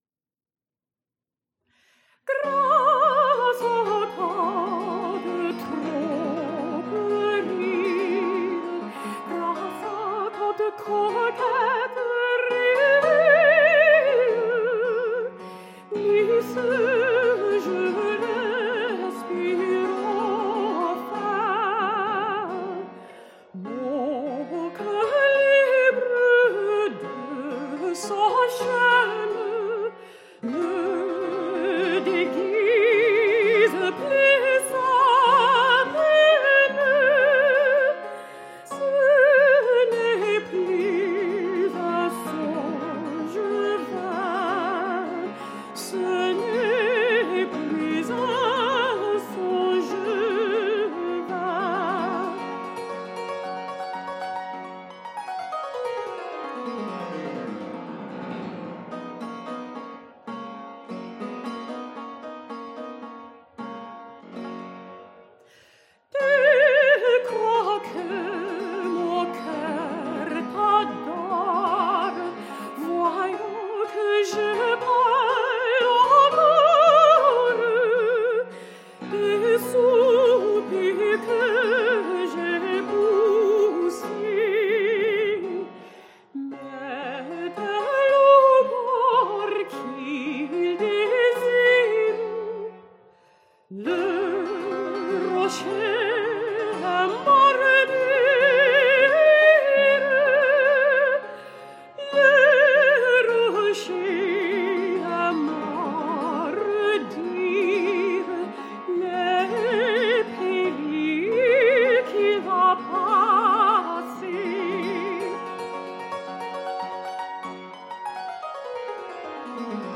PerformerThe Raritan Players
Subject (lcsh) Romances (Music)